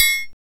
percussion 36.wav